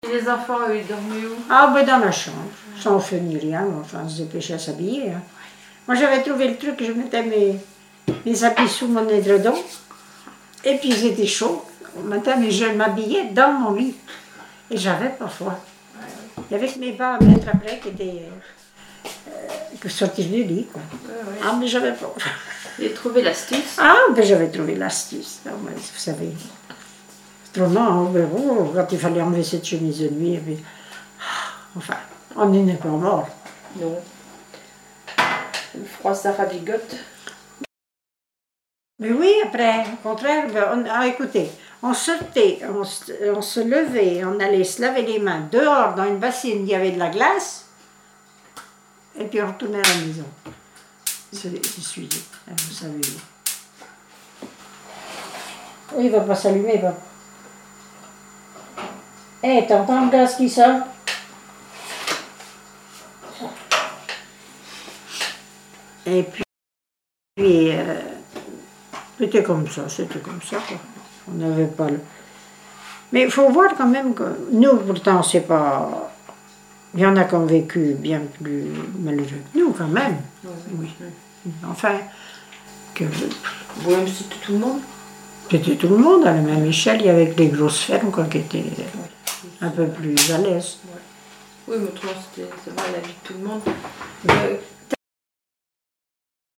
Catégorie Témoignage